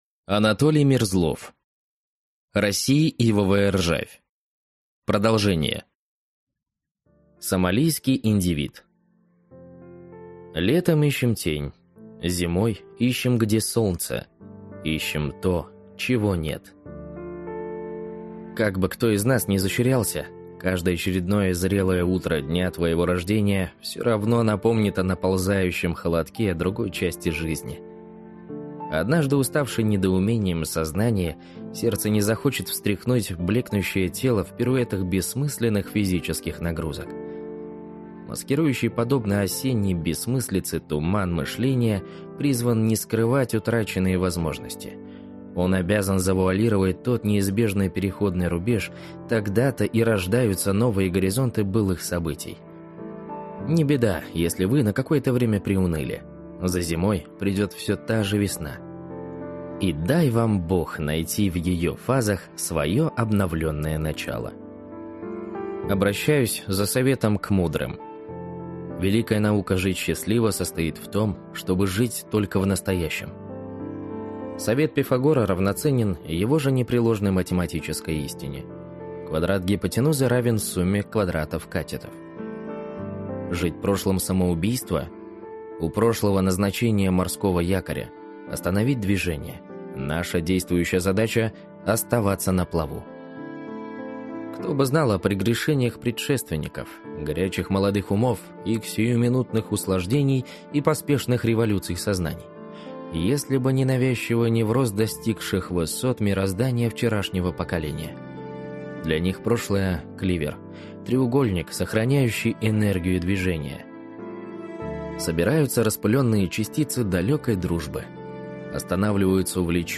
Aудиокнига России ивовая ржавь.